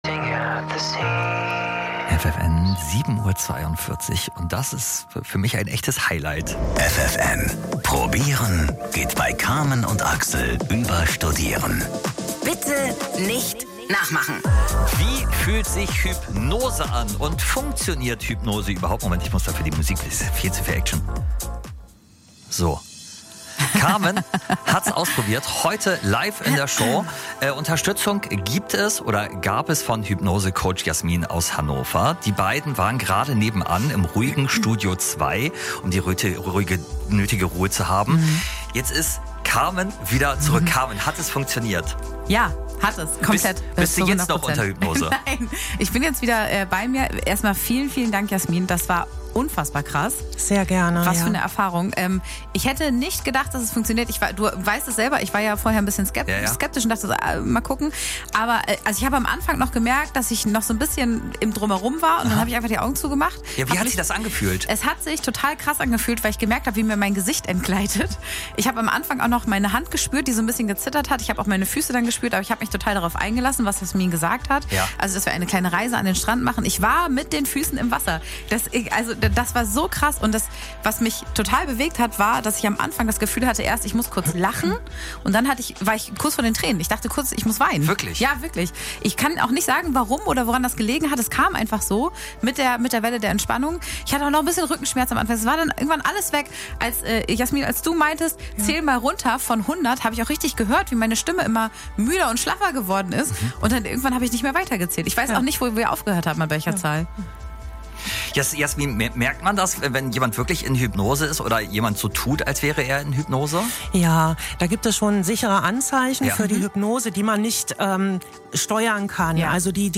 Ich hatte mein erstes Interview bei Radio FFN – eine absolute Premiere für mich und unglaublich spannend!